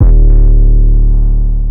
808 (Still Here).wav